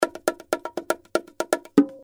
120 BPM - bongo loops (15 variations)
This package contains 15 bongos loops and fills.
Qty: $0.00 Real bongo loops at 120 bpm.
We recorded the bongos in very high quality sound,we used, 3 microphones, (AKG C-12 VR , 2 x AKG 451B for room and stereo). All the loops are stereo 44100 Hz Wav quality.